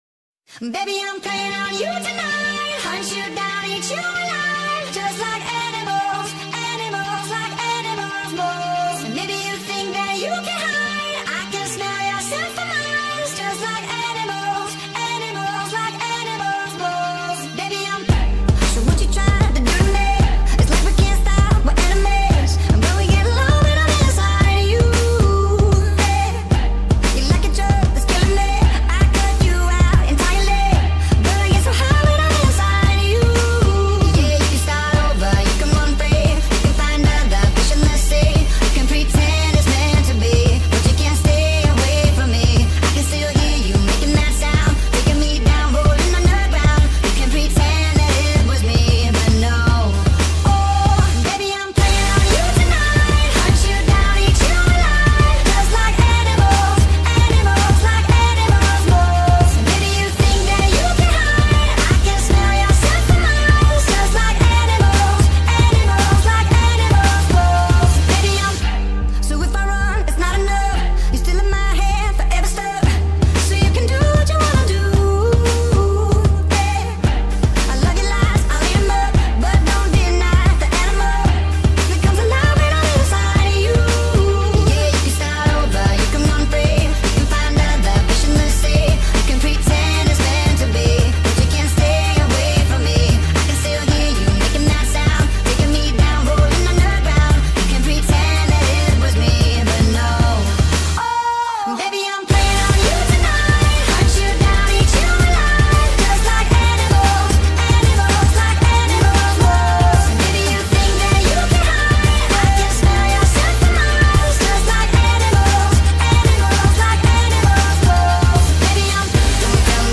با ریتمی تند
شاد